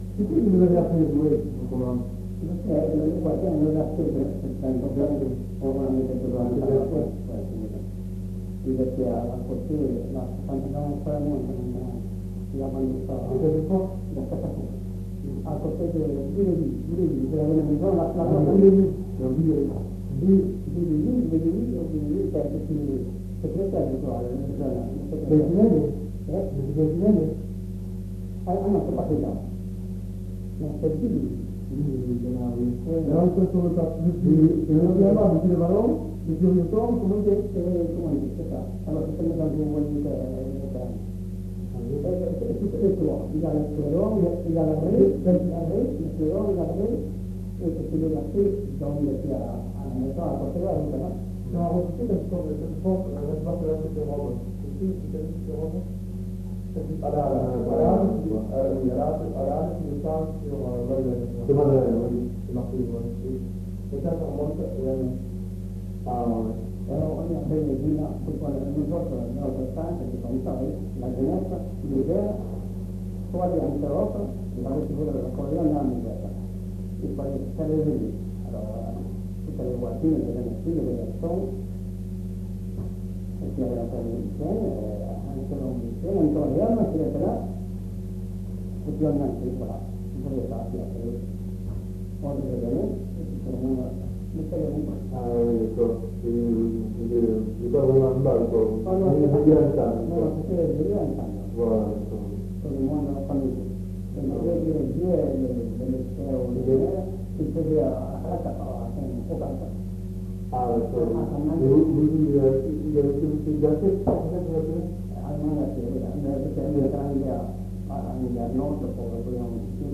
Lieu : Escassefort
Genre : témoignage thématique